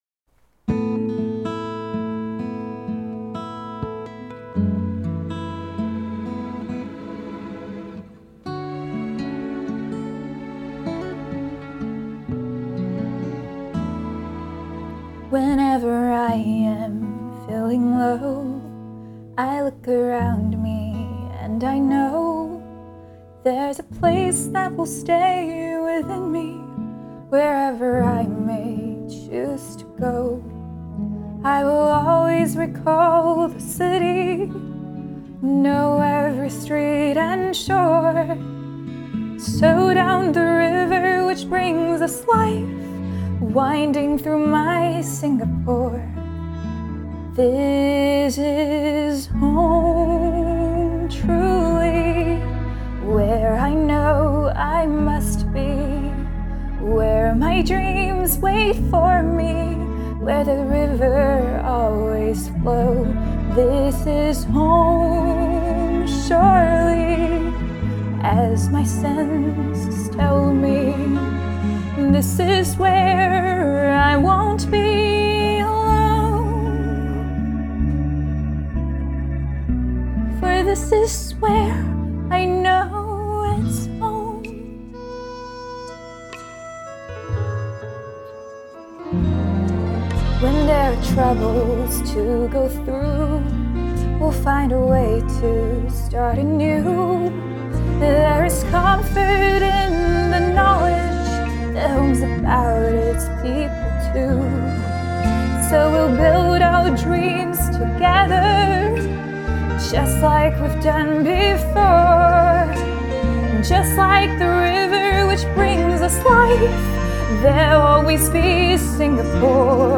😁😁😁,this is so good. beautiful voice and article, thank you.